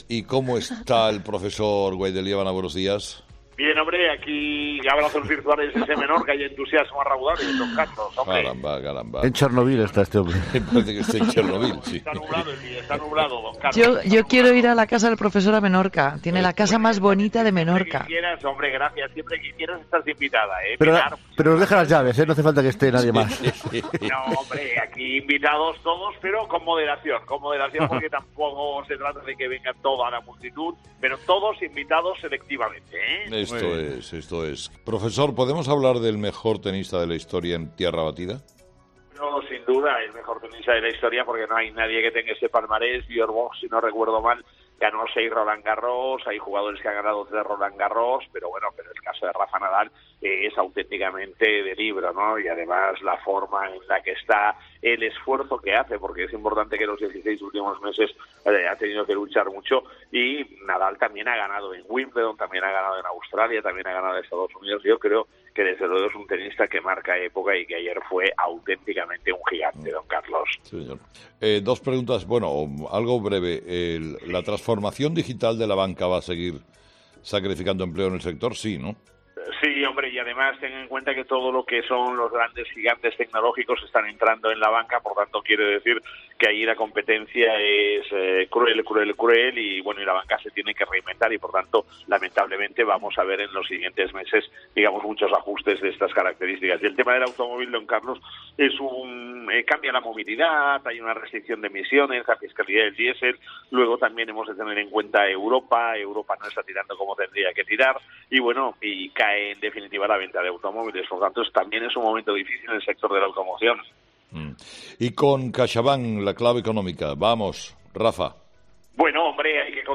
El profesor Gay de Liébana desde Menorca